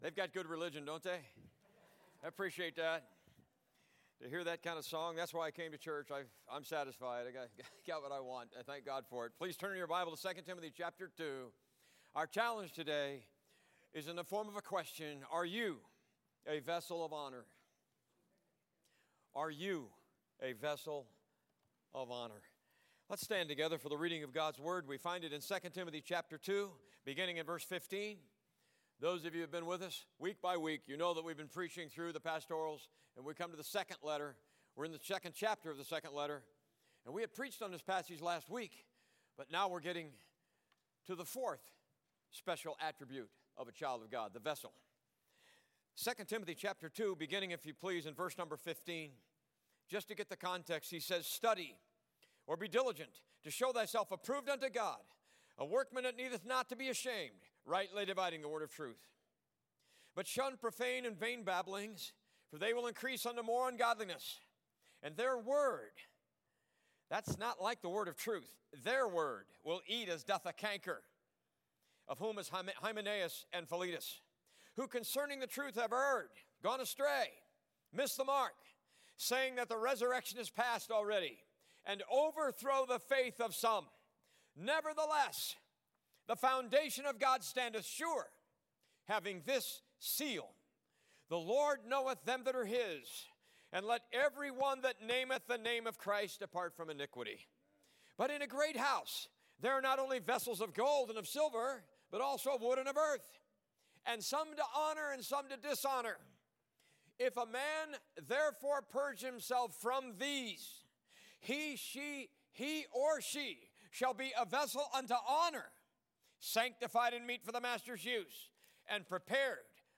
Service Type: A.M. Service